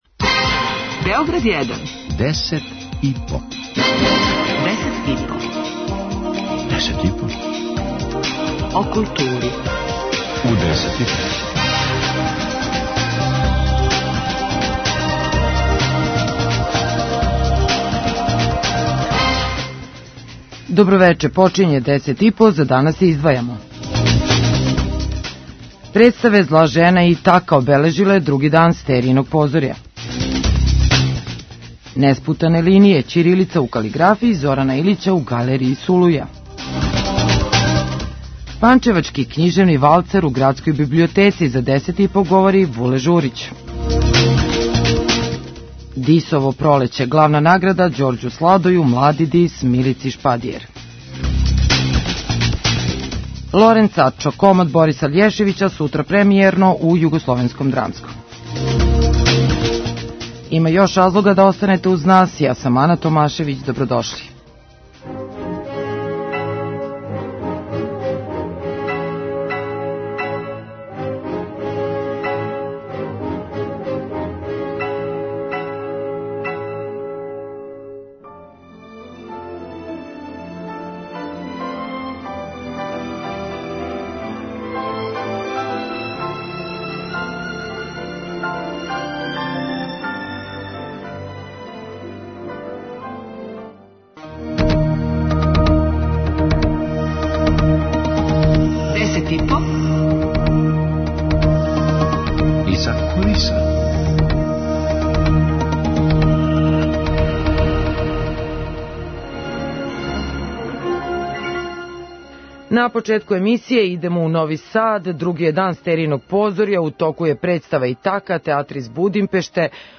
преузми : 5.36 MB Десет и по Autor: Тим аутора Дневни информативни магазин из културе и уметности.